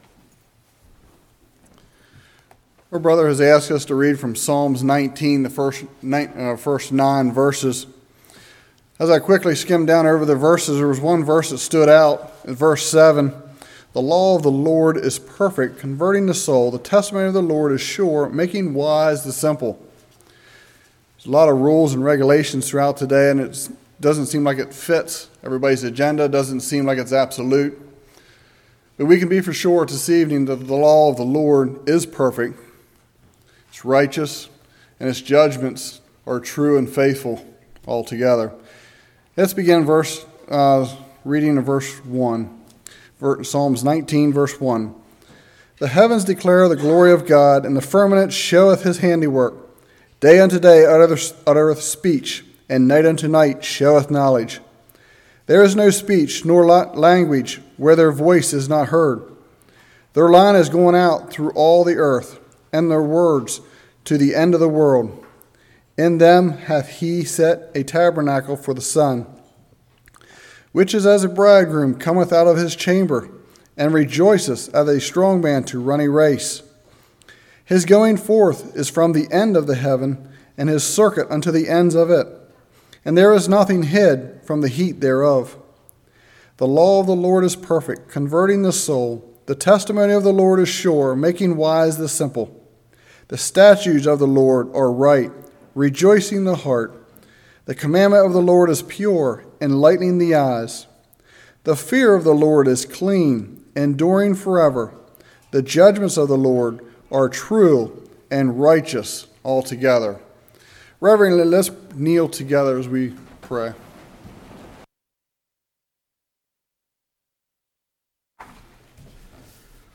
Psalms 19:1-9 Service Type: Evening The Author Is Move By Nature to Worship God.